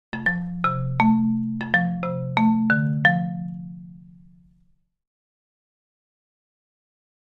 Marimba, Accent, Short Happy Melody, Type 1